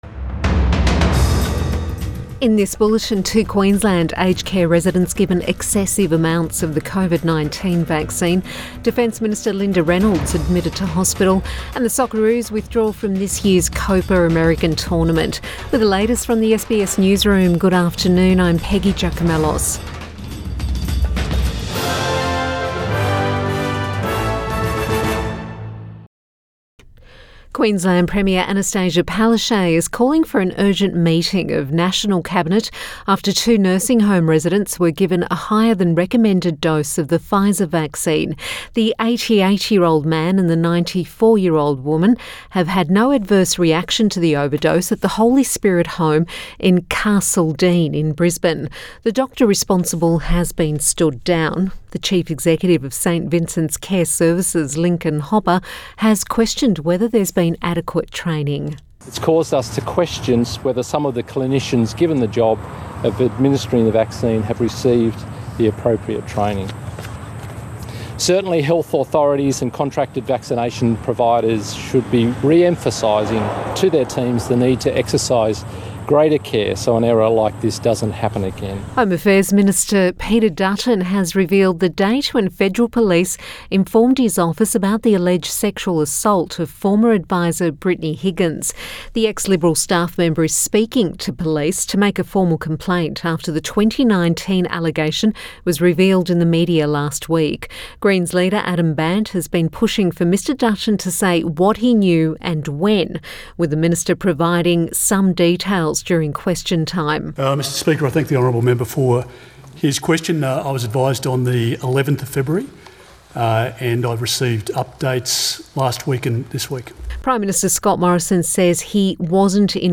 PM bulletin 24 February 2021